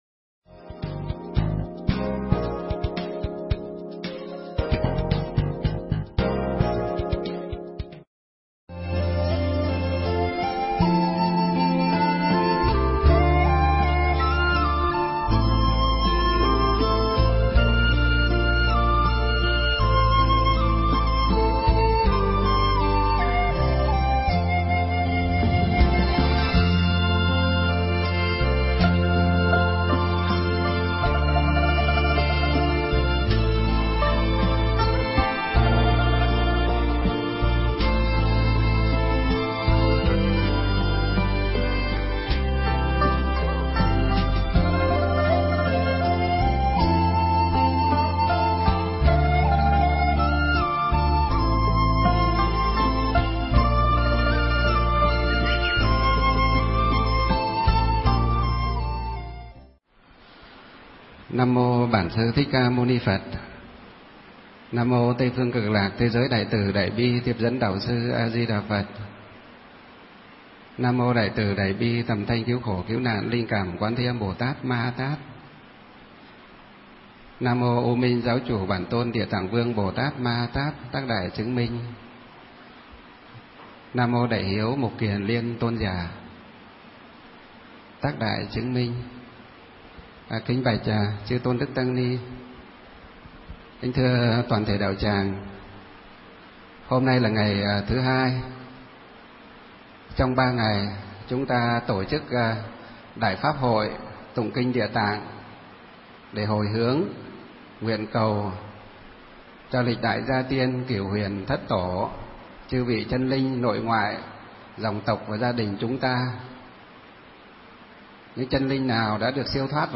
Thể loại: Giảng Pháp